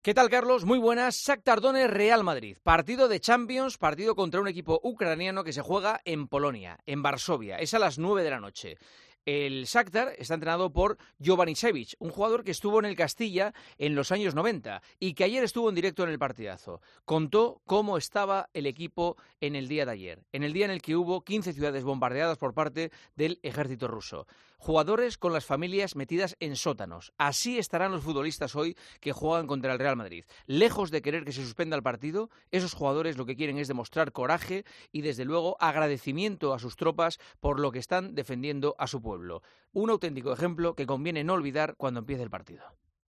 El comentario de Juanma Castaño